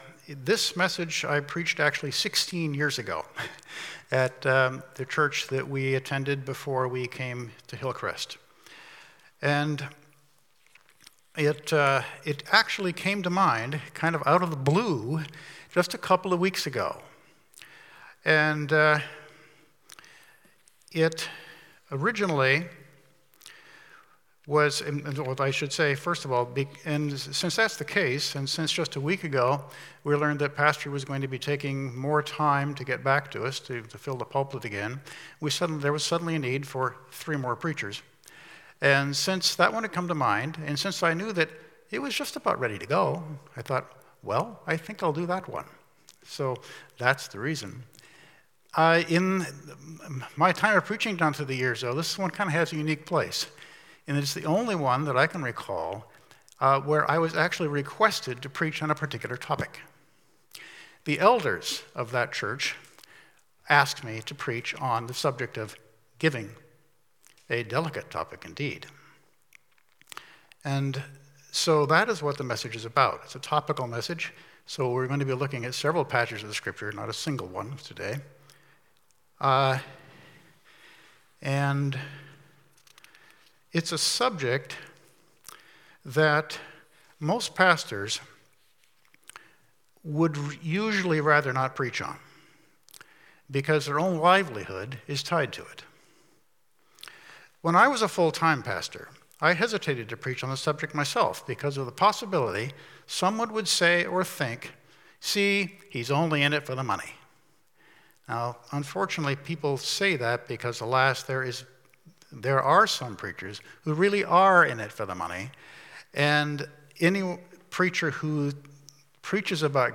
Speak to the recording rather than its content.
Mark 12:41-44 Service Type: Morning Worship Service Topics